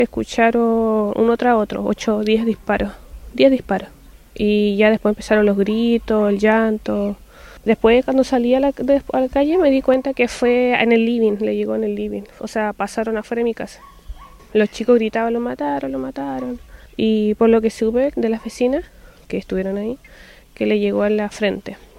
cuna-baleo-testigo.mp3